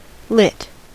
Ääntäminen
IPA : /ˈlɪt/